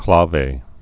(klävā)